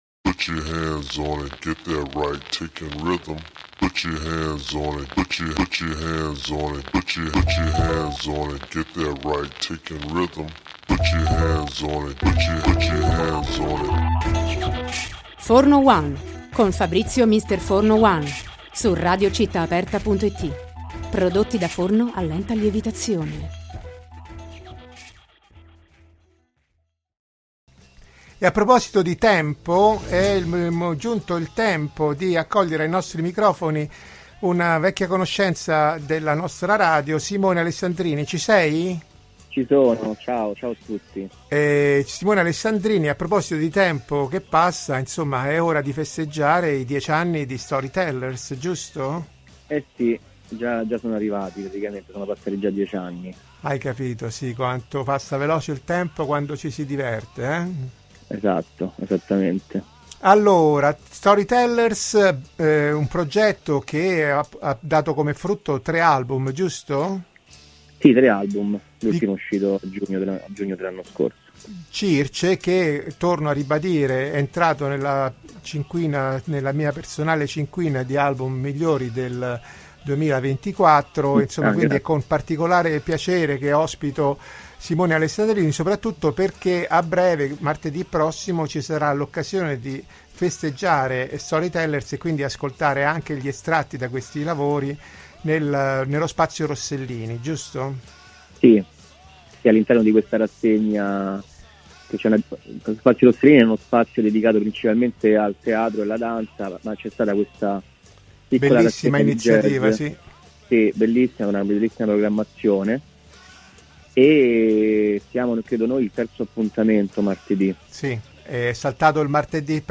ha raggiunto telefonicamente